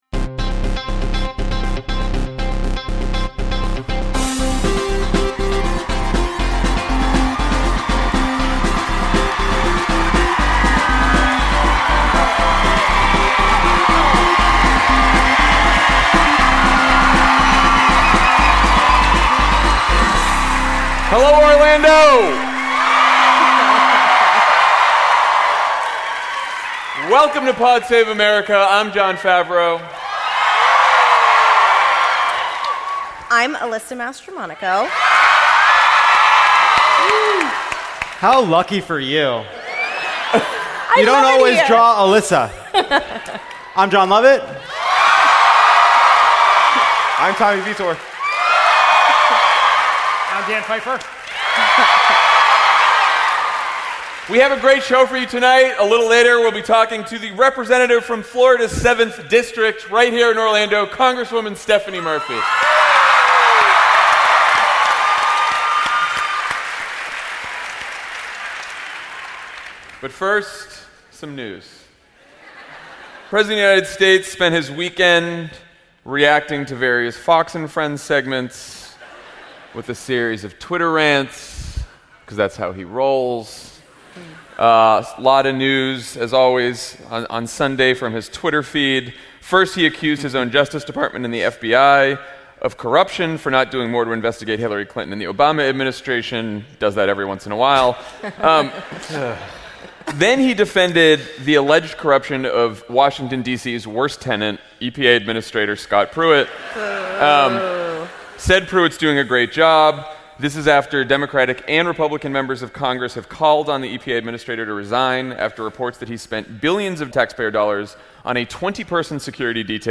“Xi likes me!” (LIVE from Orlando)